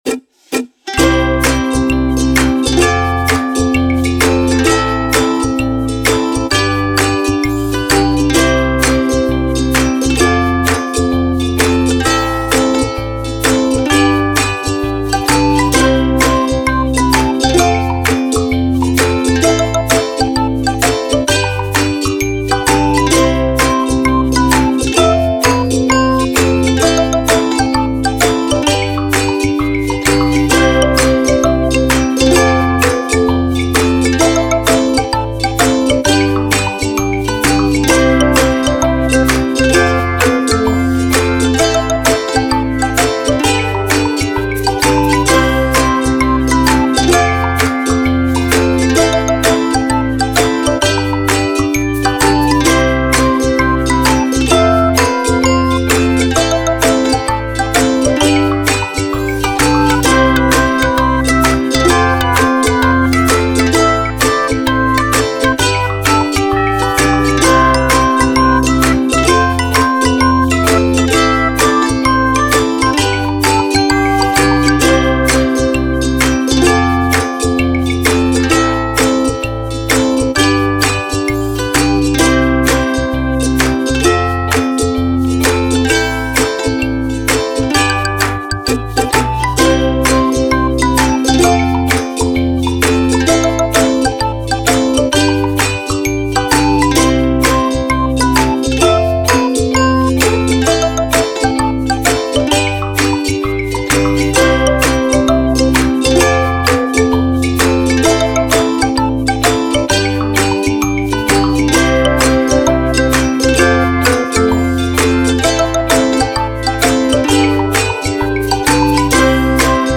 雰囲気 　かわいい / クール
ウクレレの楽しい曲だよ